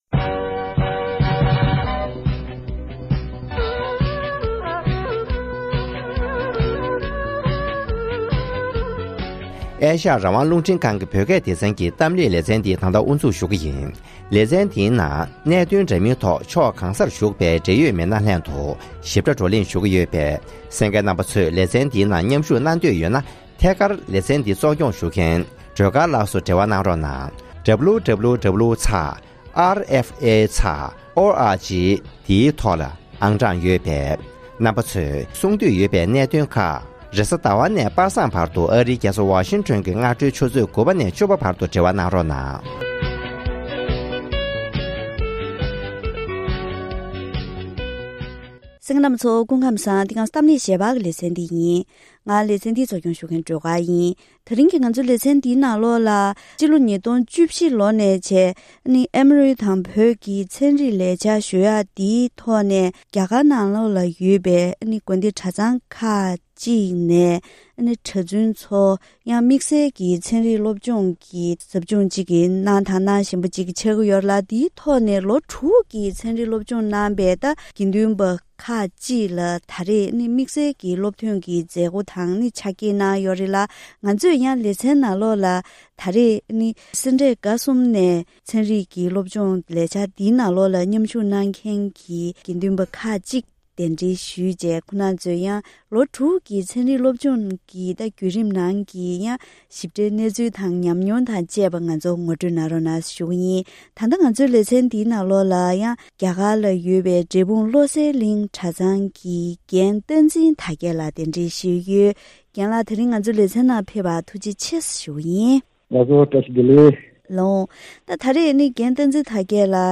༄༅༎དེ་རིང་གི་གཏམ་གླེང་ཞལ་པར་ལེ་ཚན་ནང་རྒྱ་གར་གྱི་གདན་ས་ཁག་ནང་ཨེ་མོ་རི་དང་བོད་ཀྱི་ཚན་རིག་ལས་འཆར་ཁོངས་ནས་ལོ་དྲུག་གི་ཚན་རིག་སློབ་སྦྱོང་གནང་མཁན་དགེ་འདུན་པ་ཚོར་སློབ་ཐོན་གྱི་ཕྱག་འཁྱེར་གནང་ཡོད་ཅིང་།